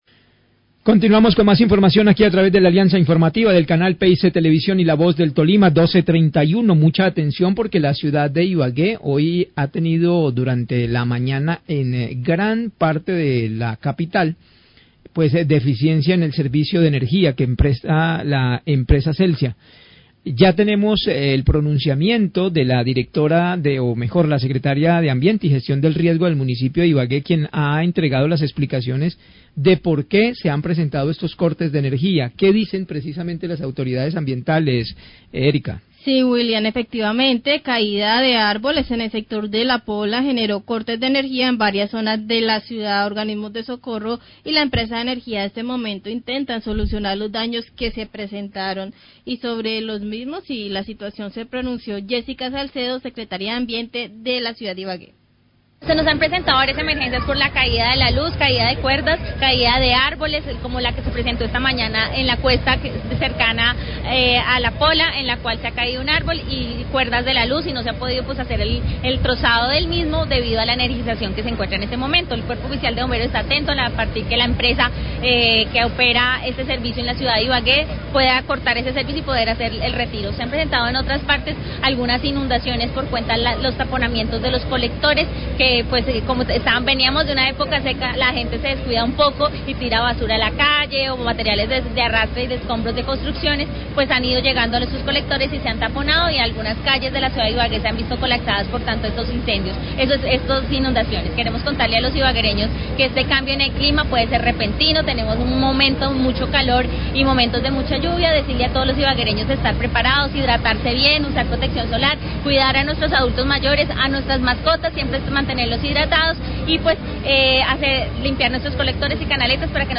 Radio
La Secretaria de Ambiente y Gestión del Riesgo de Ibagué, Yesica Salcedo, habla de las afectaciones por fuetes lluvias que dejaron como  saldo la caída de árboles sobre redes de energía y que generaron cortes del  servicio en el sector de La Pola.